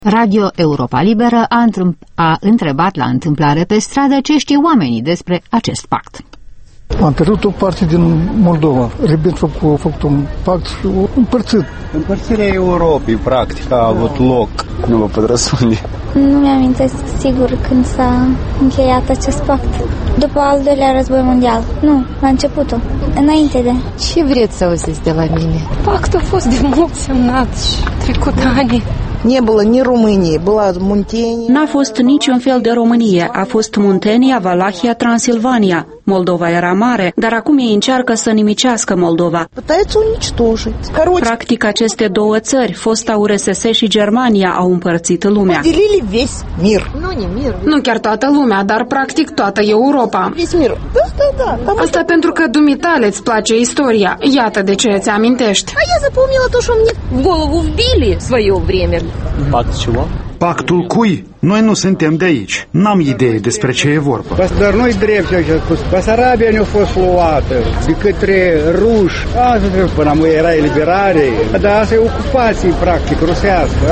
Vox pop la Chișinău despre puțină istorie